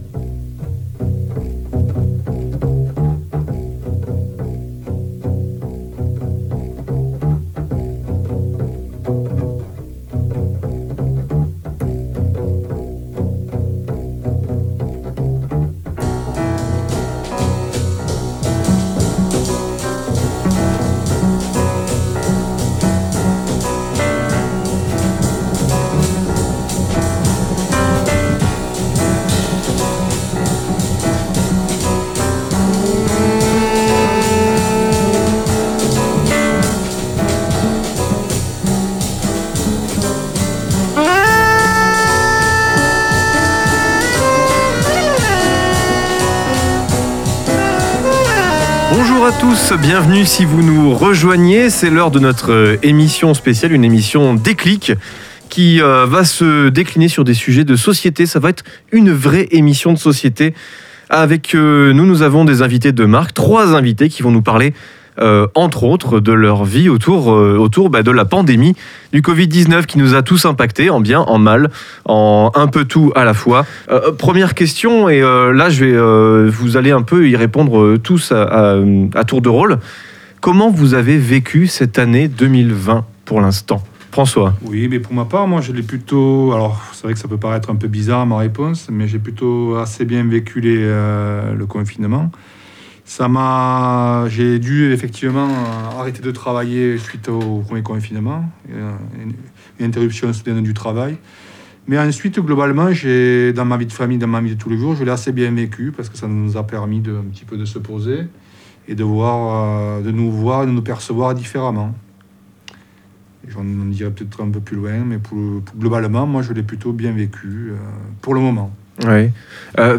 Le temps d'un débat, d'une émission, les stagiaires du programme Déclic se retrouvent pour échanger sur leur vécu, leur ressenti, les changements et les idées qui les ont touchés pendant la crise du Covid19.